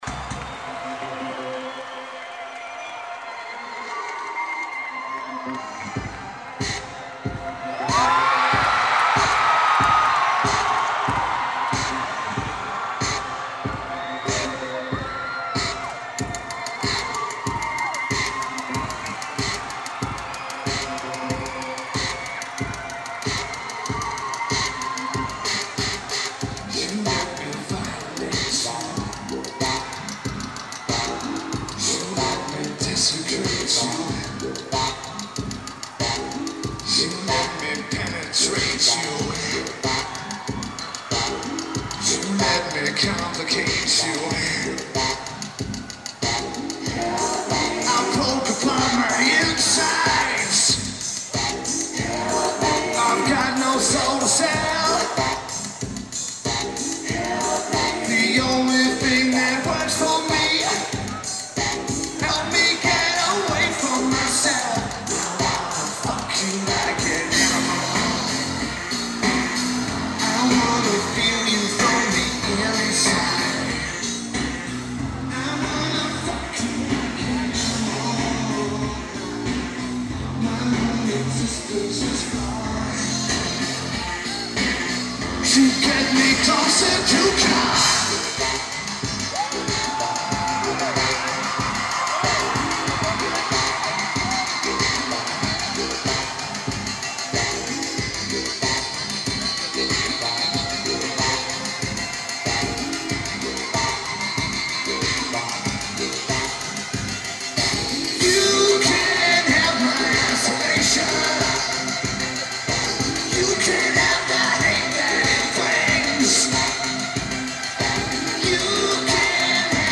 Madison Square Garden
Lineage: Audio - AUD (Clip On Mic + Sony MD)